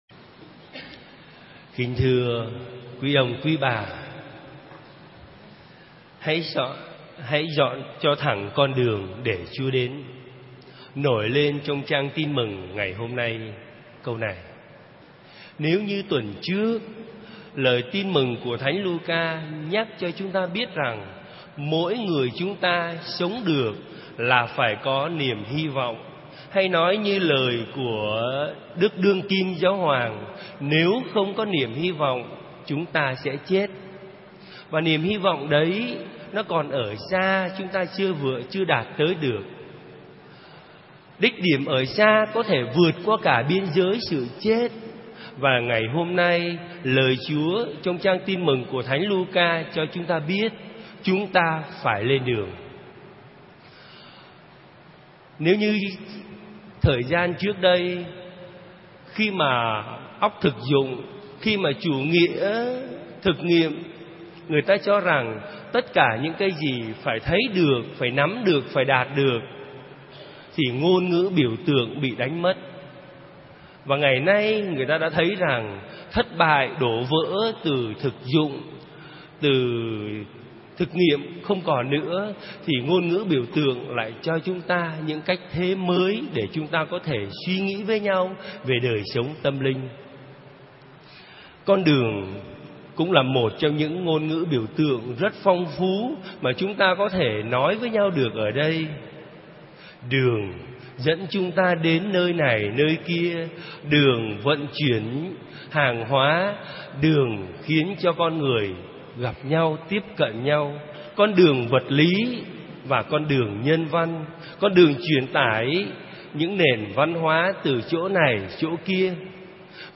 Dòng nhạc : Nghe giảng